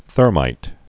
(thûrmīt)